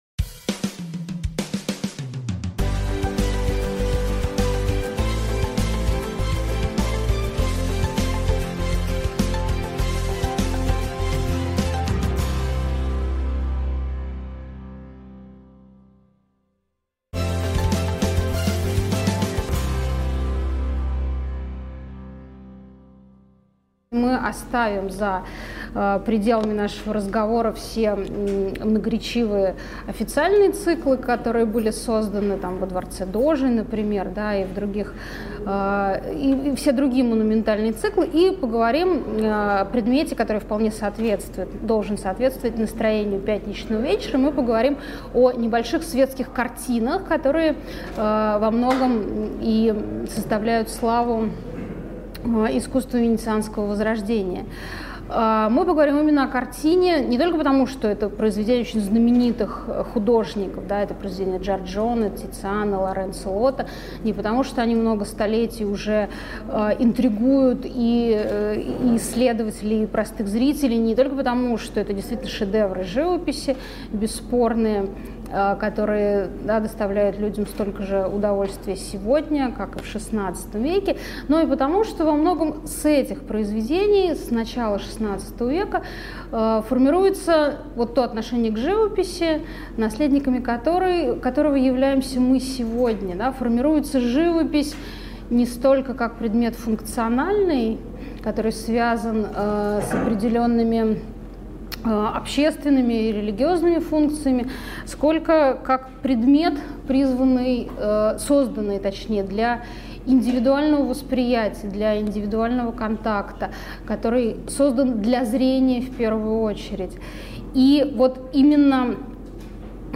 Аудиокнига Светская живопись венецианского Возрождения | Библиотека аудиокниг